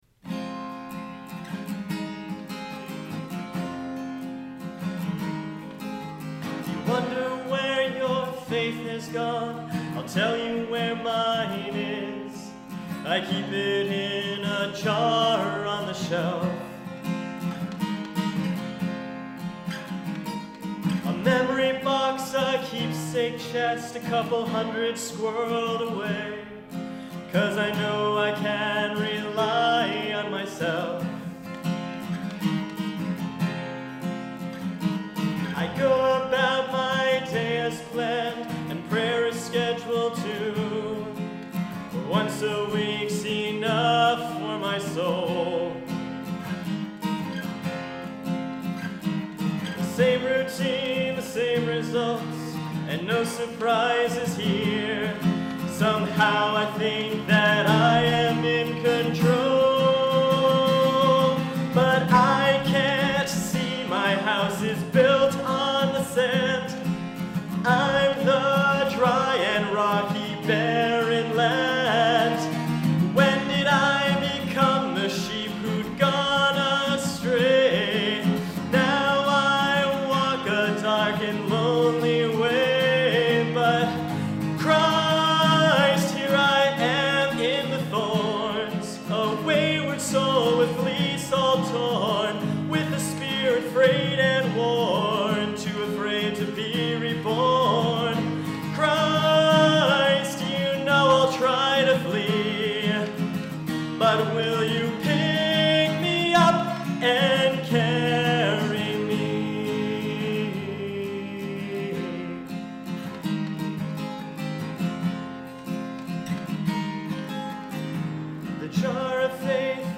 New Songs (recorded live during YouTube Morning Prayer services)
carry-me-live.mp3